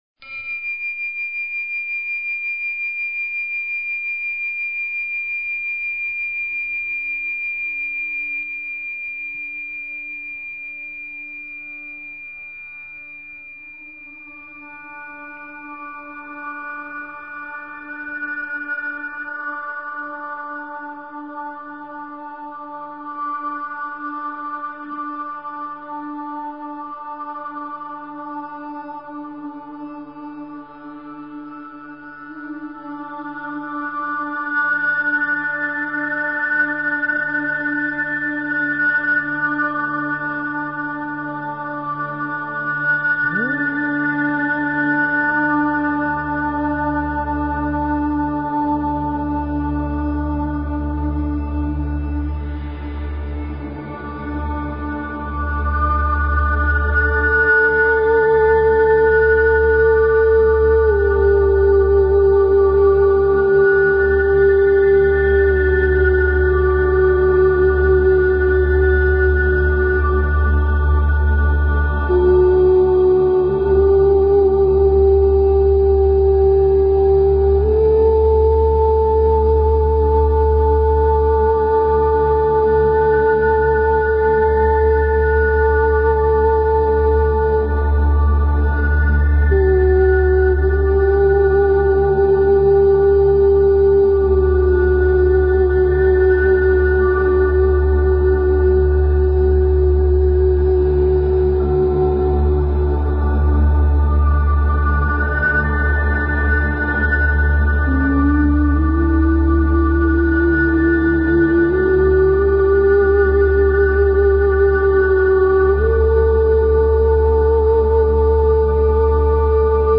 Talk Show Episode, Audio Podcast, Radiance_by_Design and Courtesy of BBS Radio on , show guests , about , categorized as
Radiance is a call in show so call in about your life, your questions, the trickery that you find in your daily routine.